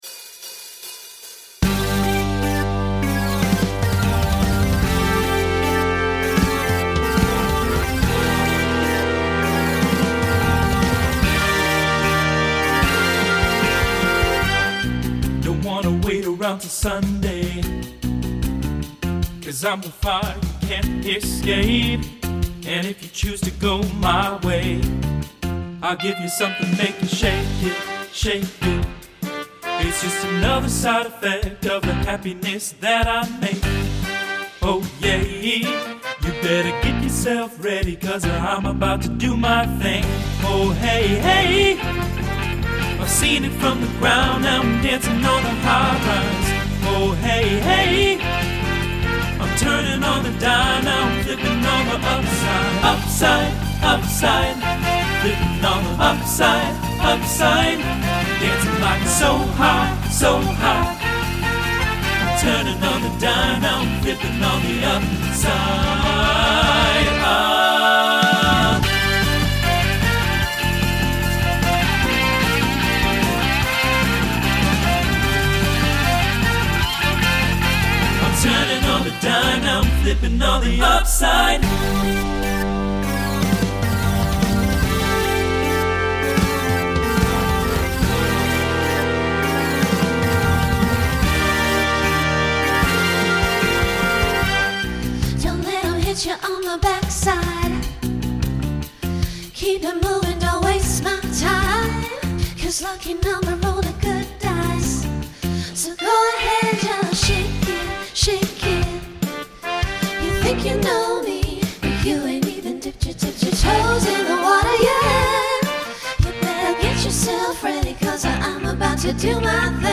TTB/SSA/SATB
Genre Pop/Dance
Transition Voicing Mixed